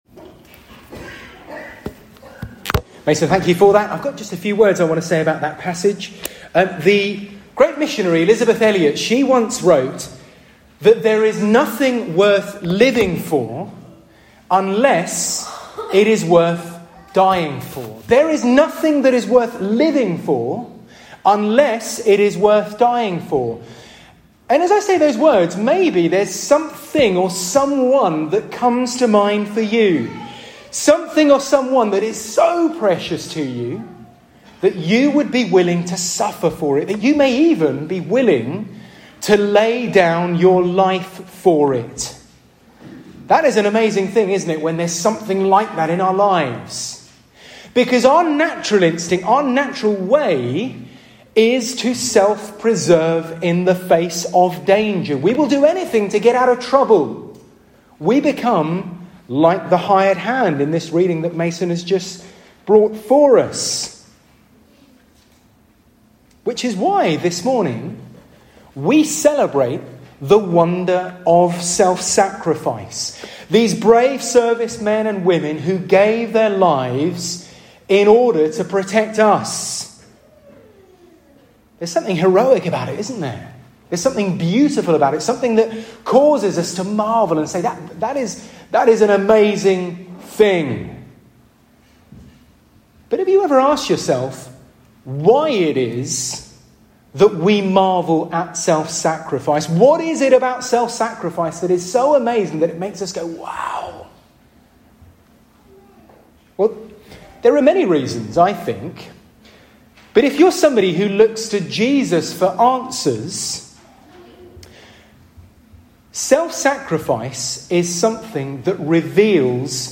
SERMON-10TH-NOVEMBER.mp3